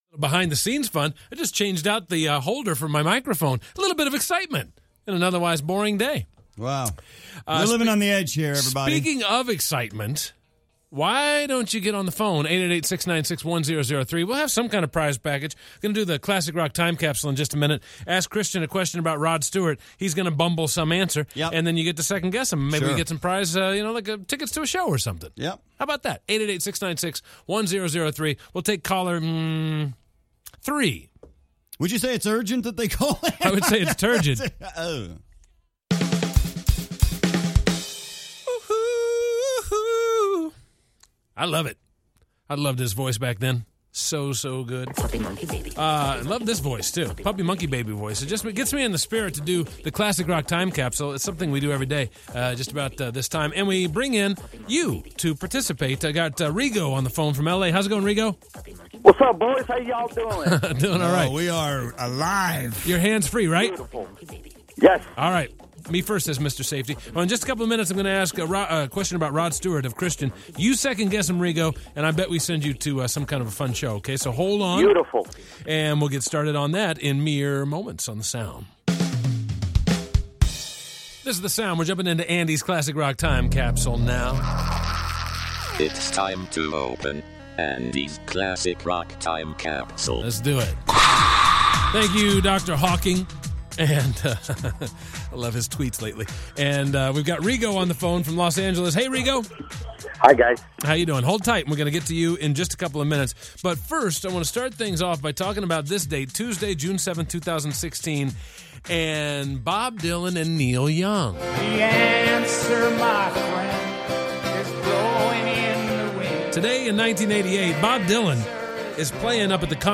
play Giant Jenga in the studio